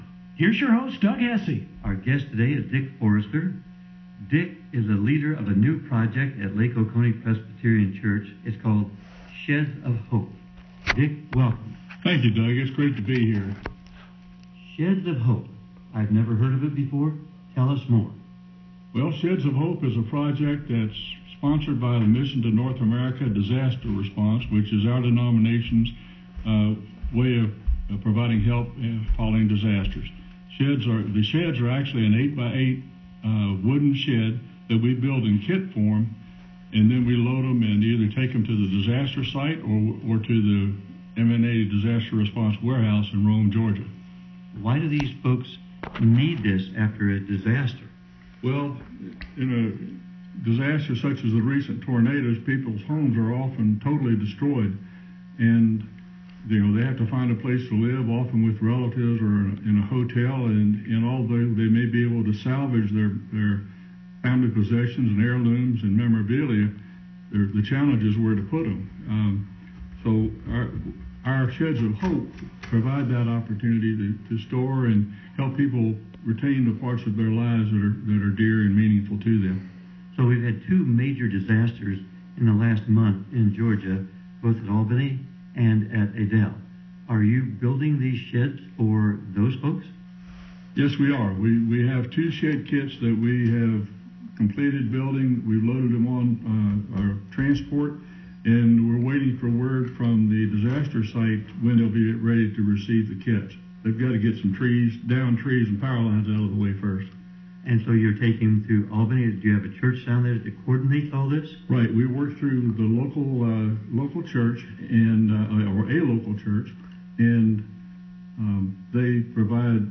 sheds-of-hope-radio-interview-21feb17-dhf.m4a